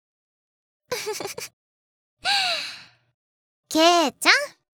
ボイスピンナップ
の一文にグッときてしまいました.！／思い出すところなのでエコー演出入れるか悩みましたが、シンプルな素の声の方がいいなと思ったので手を加えずシンプルにさせて頂きました。)